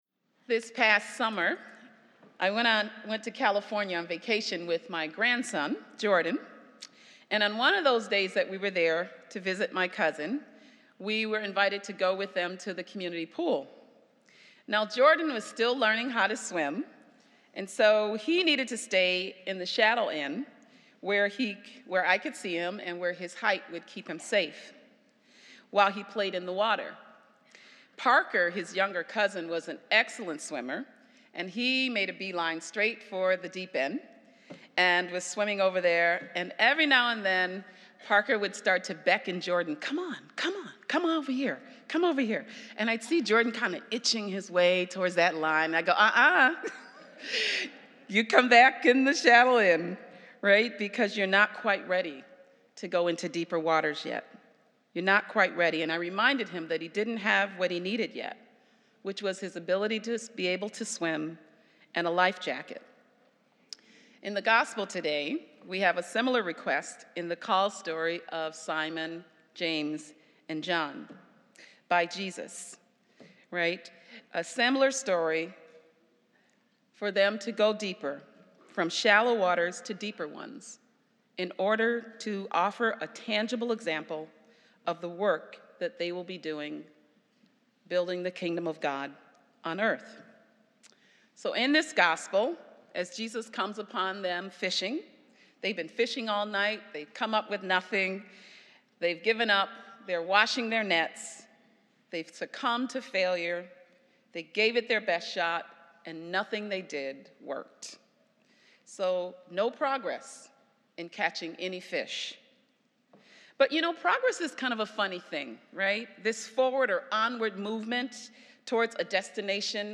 Spiritus Christi Gospel Choir is joined by their choir cousins from Zion Hill Baptist Church.